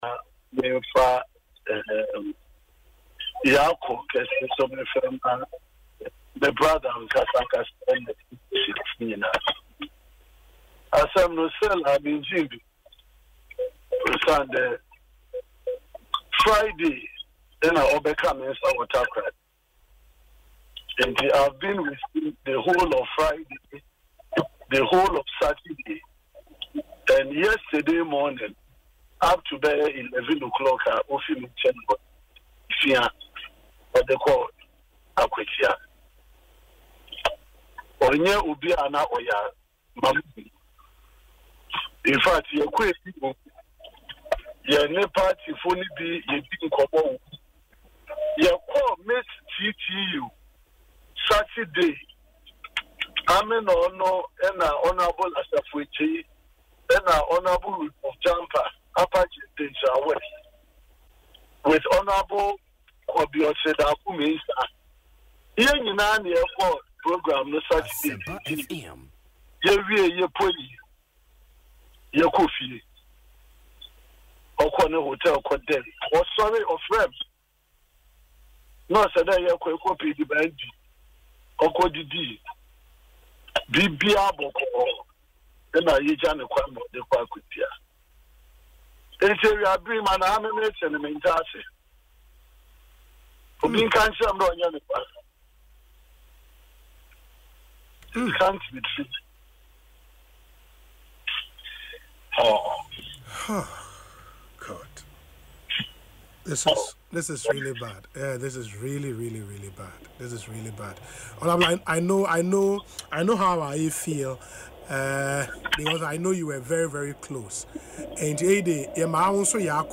Speaking in an emotional interview on Asempa FM’s Ekosii Sen programme, Mr. Boamah said he is struggling to come to terms with the news, as the late MP showed no signs of illness during the time they spent together over the weekend.